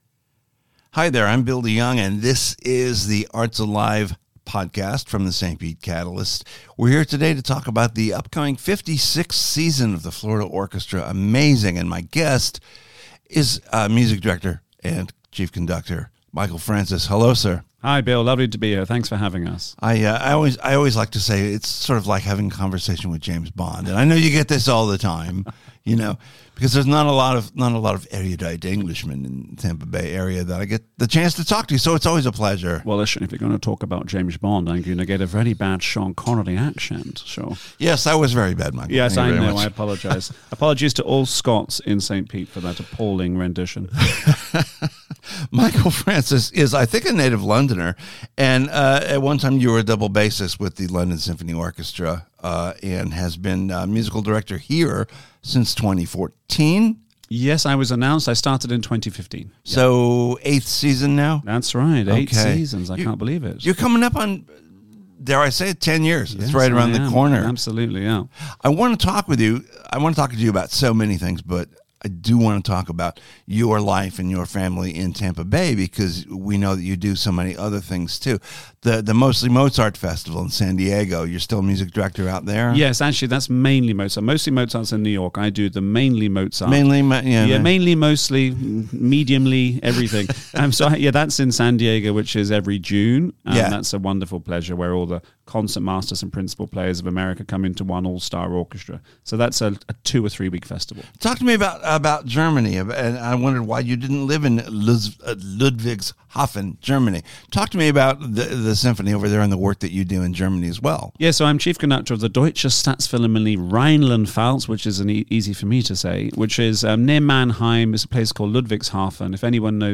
It was a freewheeling, fun conversation.